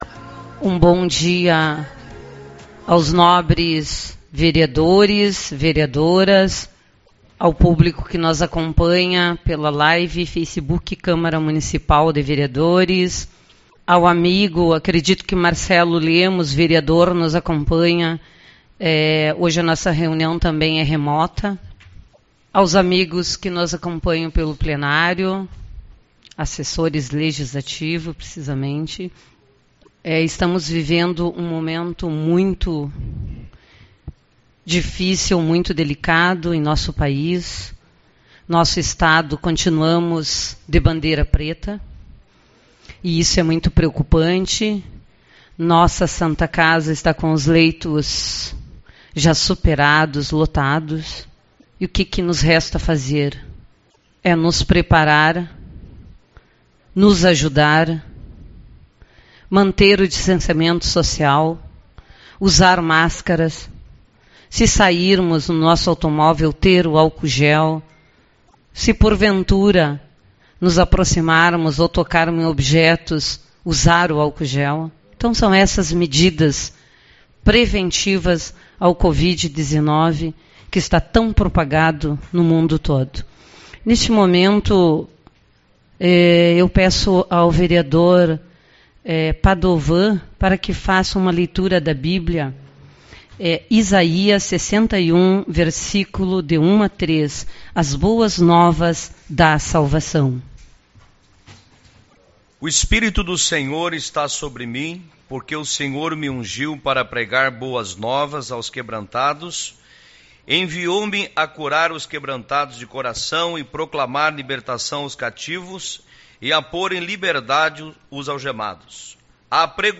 04/03 - Reunião Ordinária